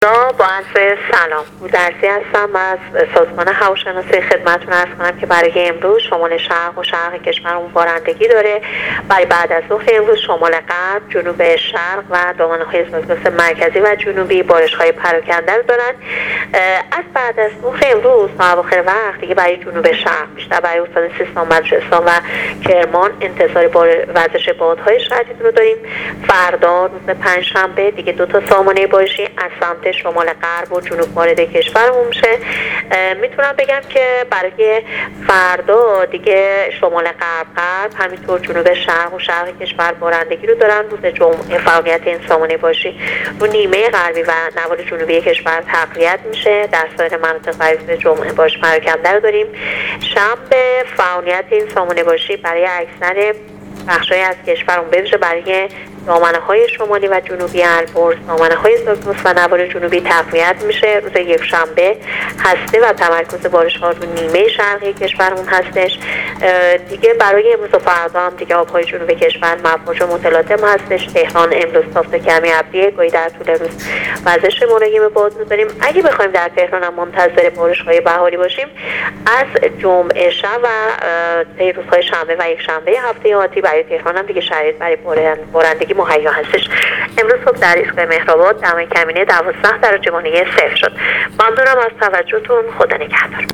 کارشناس سازمان هواشناسی در گفت‌و‌گو با راديو اينترنتی پايگاه خبری آخرين وضعيت هوا را تشریح کرد.